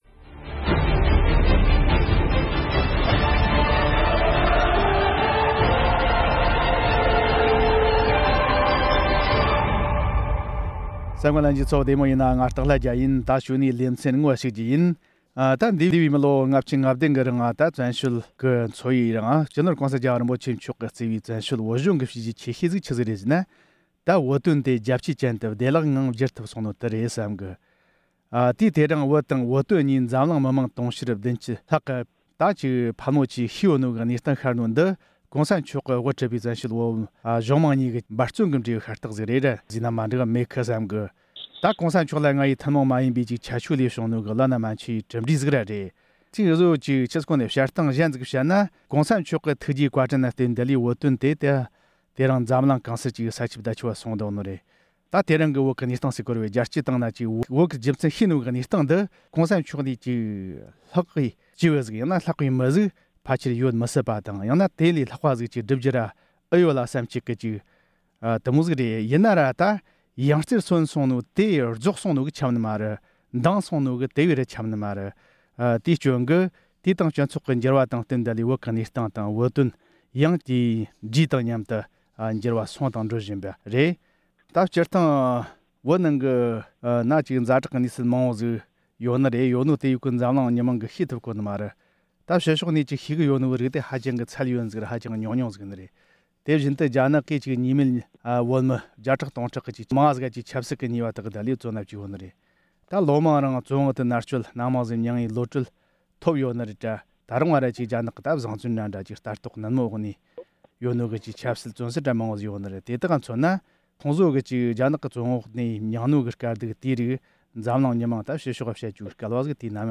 བགྲོ་གླེང་བྱེད་རྒྱུ་ཡིན།